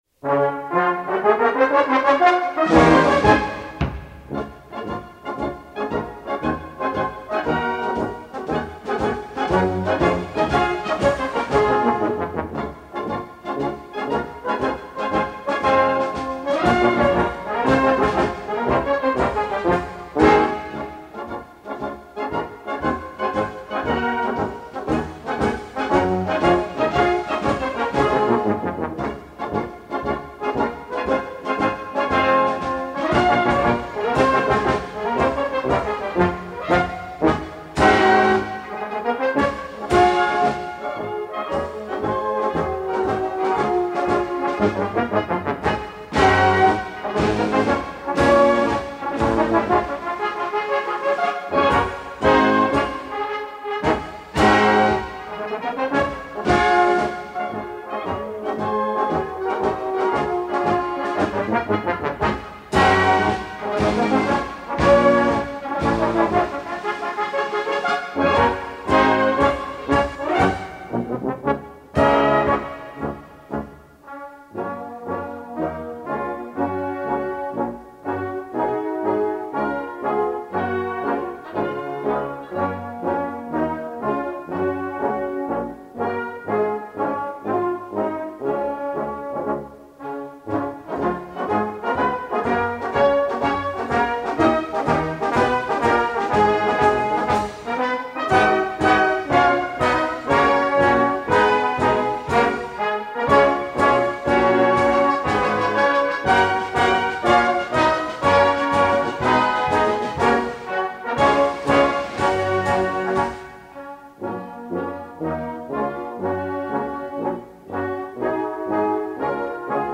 Gattung: Konzertmarsch
A4 Besetzung: Blasorchester Tonprobe